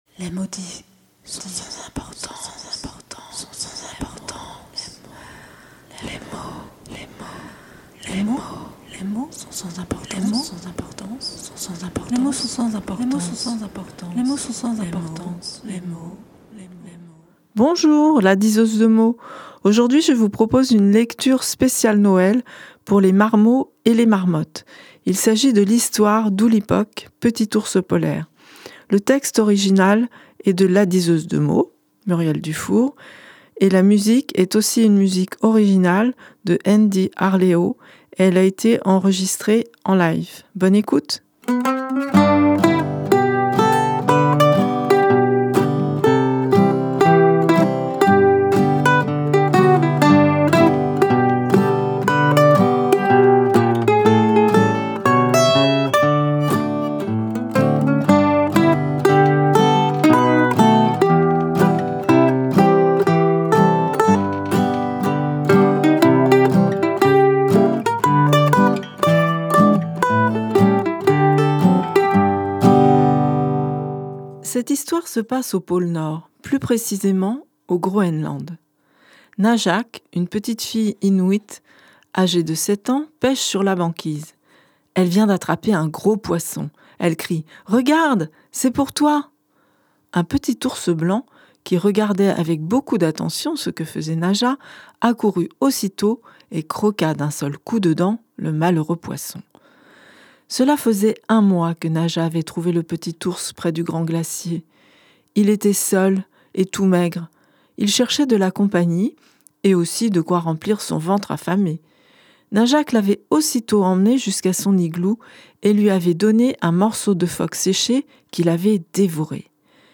Exceptionnellement, lecture pour les marmots et marmottes. Ullipoq, petit ours polaire, se retrouve à dériver sur un iceberg.